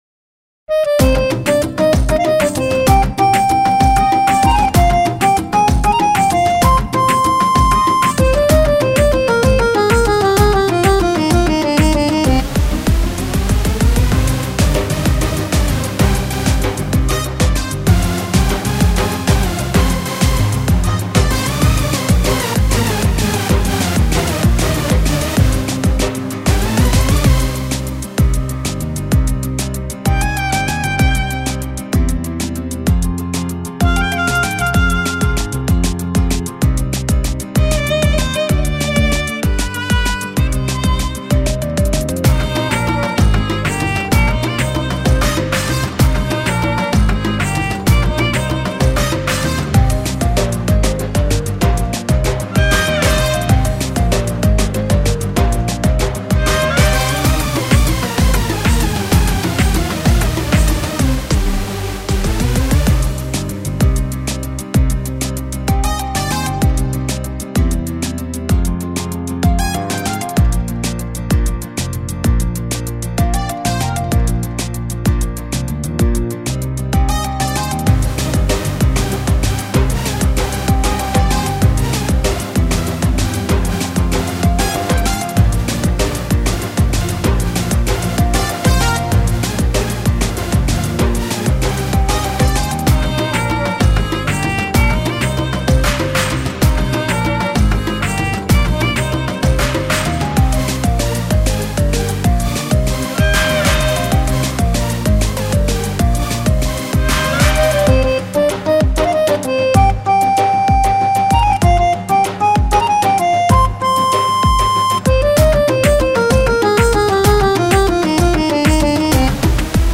آهنگ سرود
فضایی شاد و نشاط آور